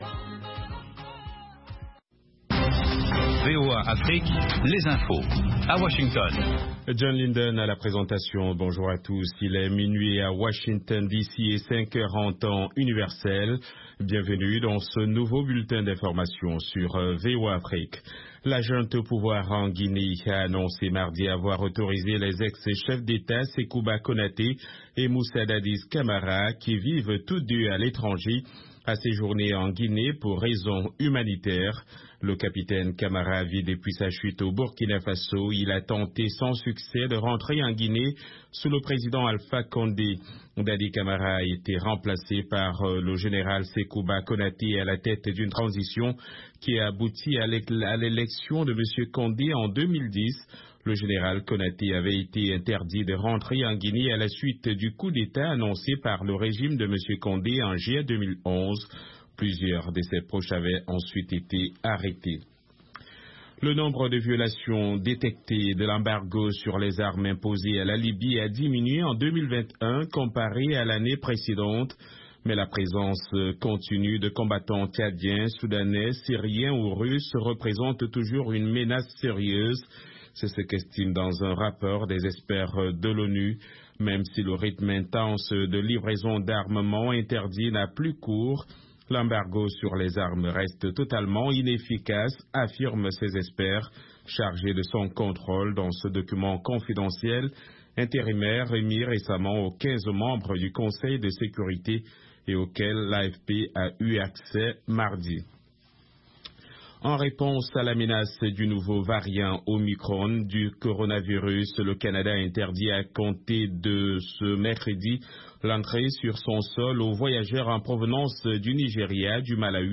5min Newscast